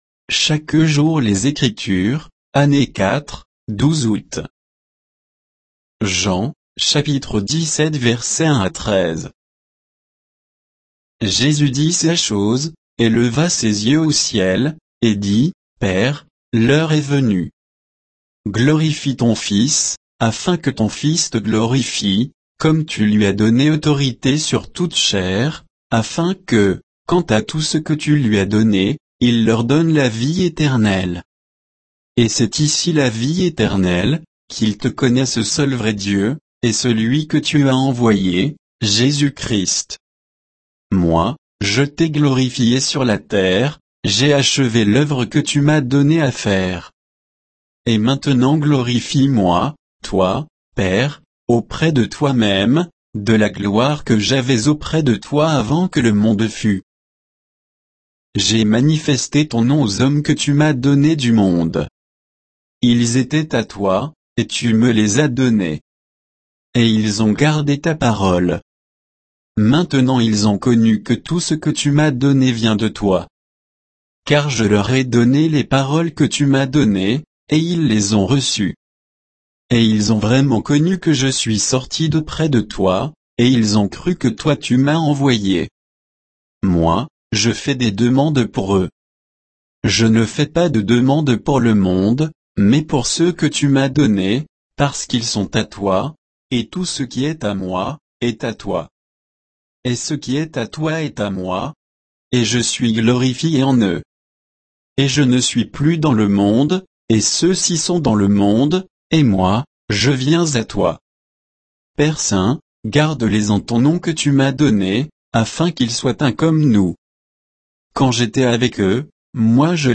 Méditation quoditienne de Chaque jour les Écritures sur Jean 17, 1 à 13